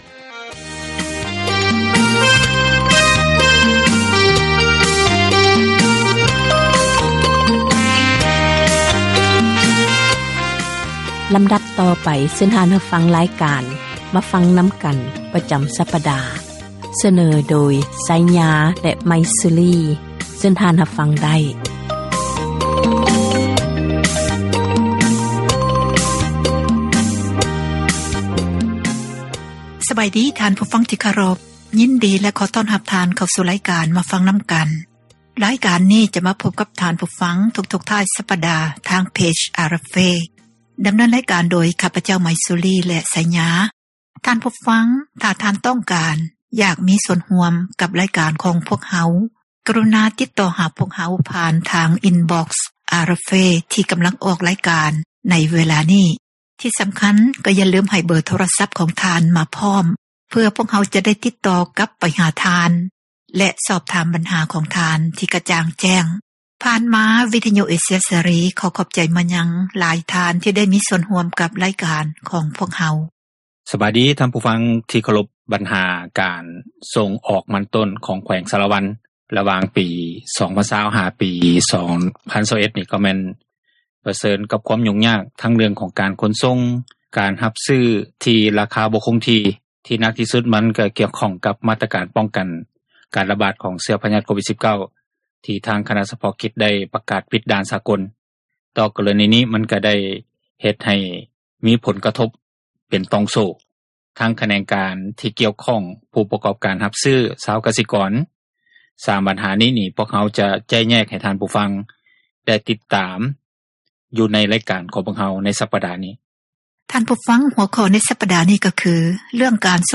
ການສົນທະນາ ໃນບັນຫາ ແລະ ຜົລກະທົບ ຕ່າງໆ ທີ່ເກີດຂຶ້ນ ຢູ່ ປະເທດລາວ.